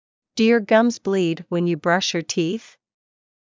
ﾄﾞｩｰ ﾕｱ ｶﾞﾑｽﾞ ﾌﾞﾘｰﾄﾞ ｳｪﾝ ﾕｰ ﾌﾞﾗｯｼｭ ﾕｱ ﾃｨｰｽ